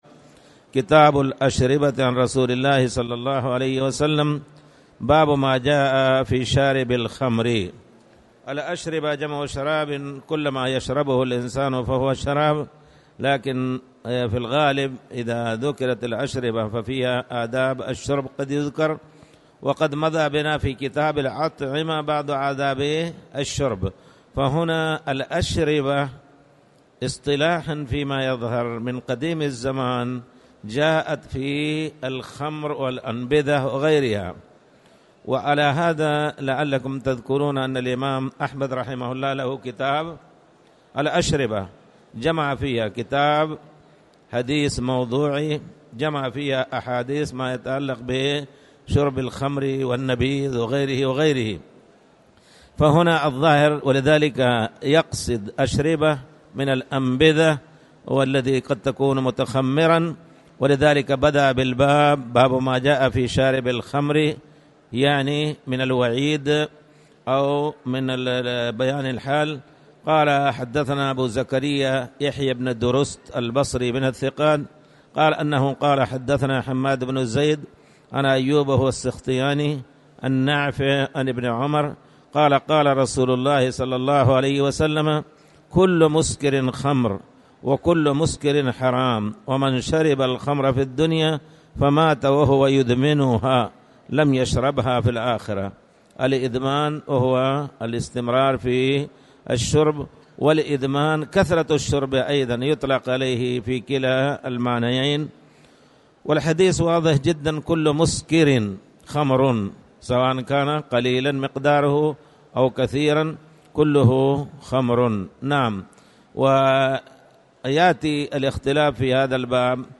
تاريخ النشر ١٤ شوال ١٤٣٨ هـ المكان: المسجد الحرام الشيخ